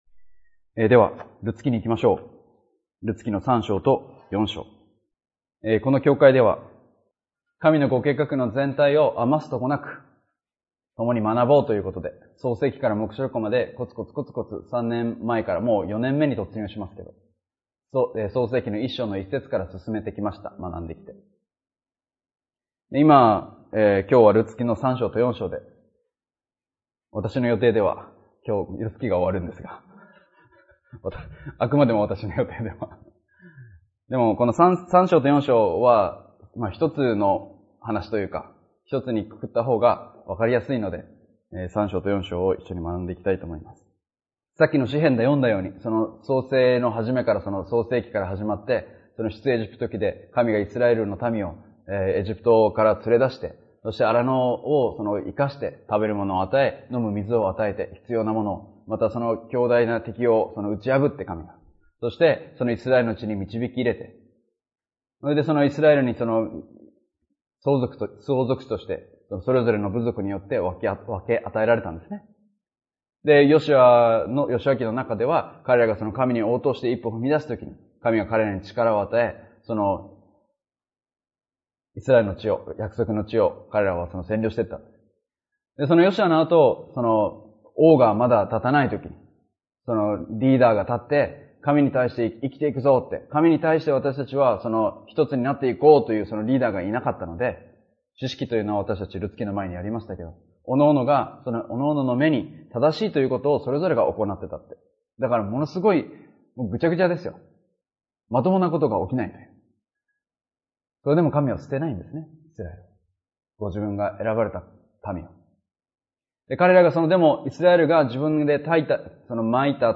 礼拝やバイブル・スタディ等でのメッセージを聞くことができます。